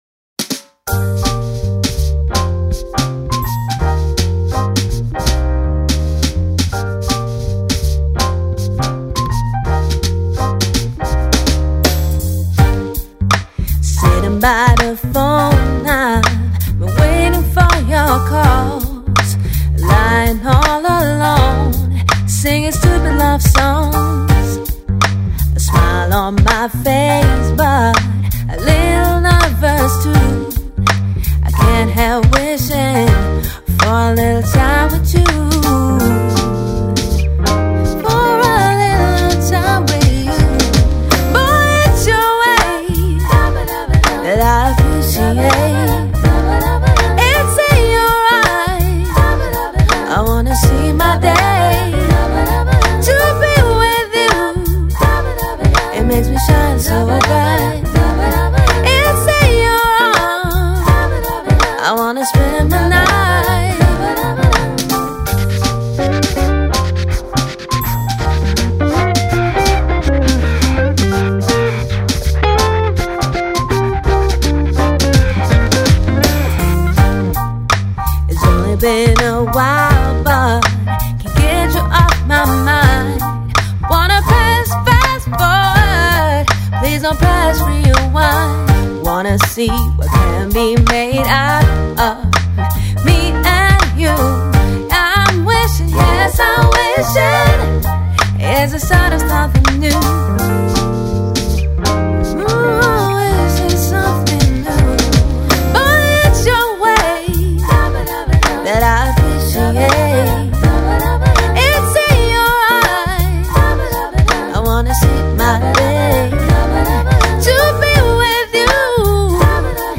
soulful female singer